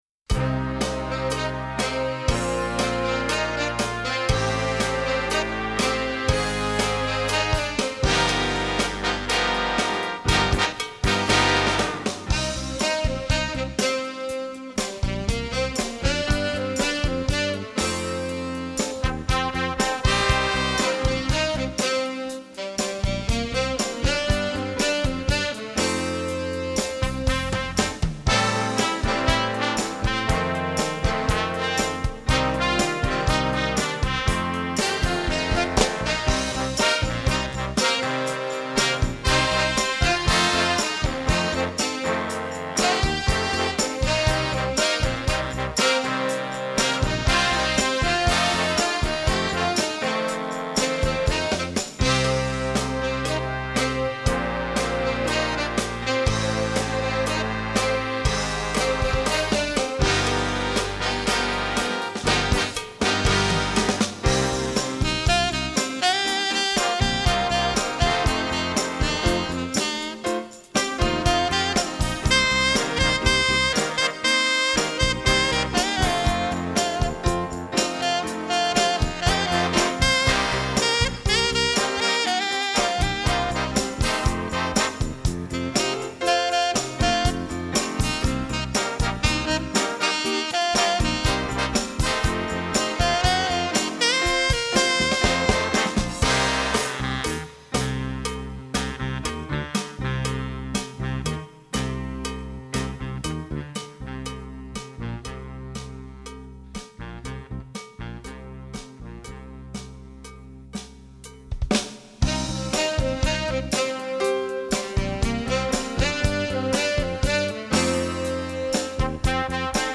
Répertoire pour Jazz band